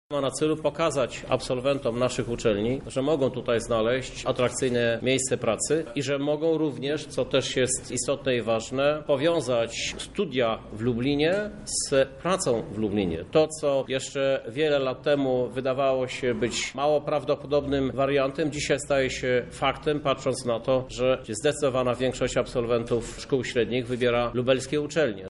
Jaki jest cel kampanii „Pracuję w Lublinie”, mówi Prezydent Miasta Krzysztof Żuk :